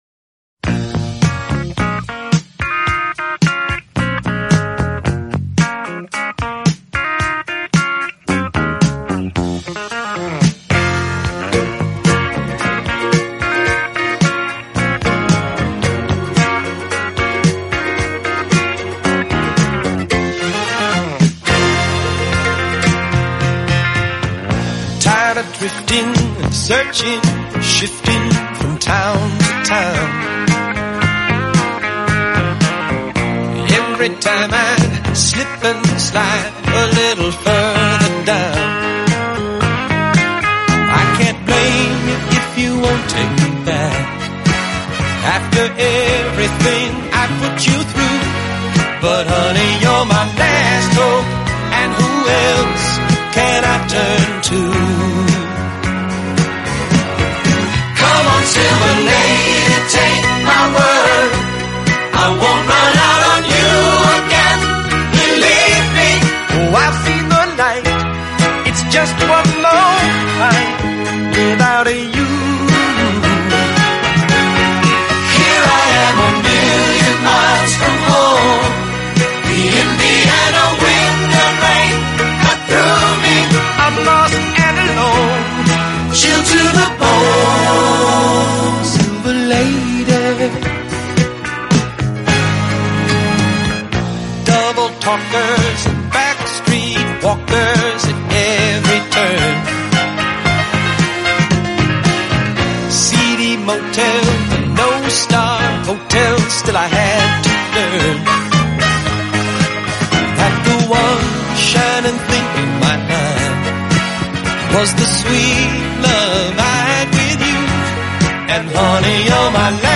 американского певца